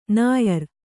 ♪ nāyar